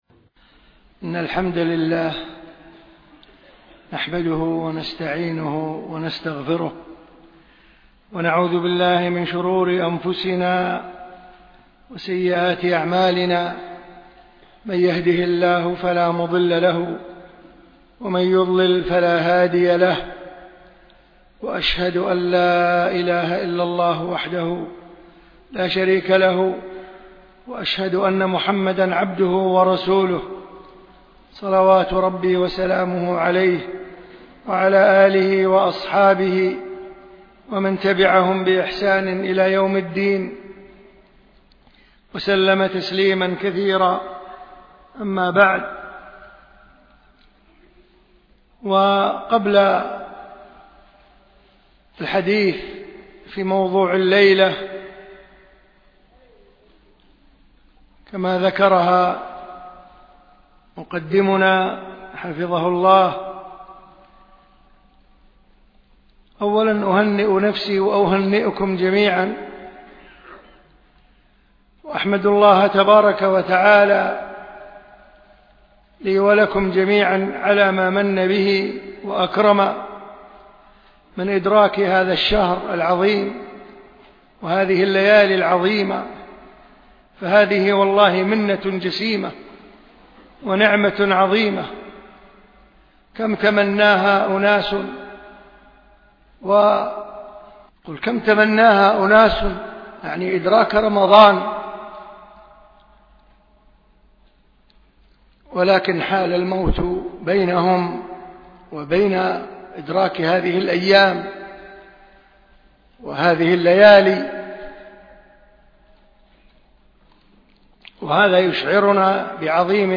ألقيت المحاضرة في دولة الإمارات